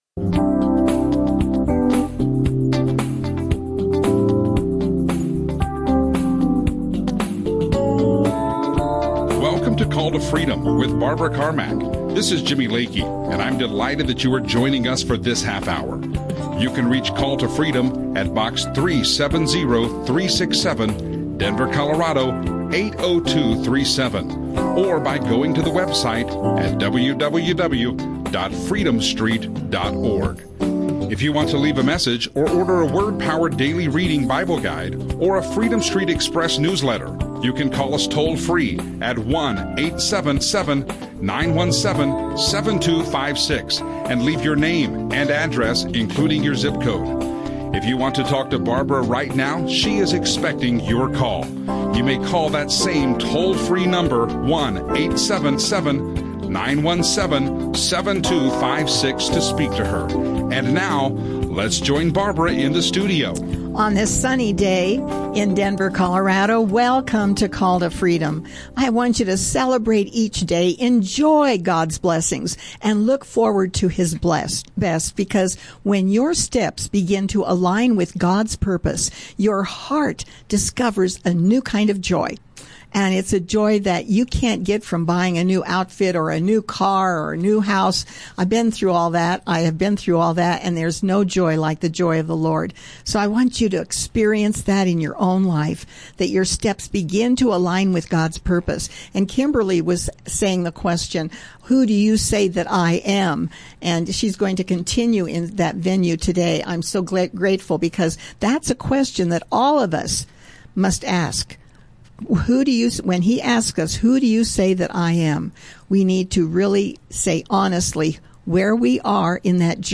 Bible teaching
Christian radio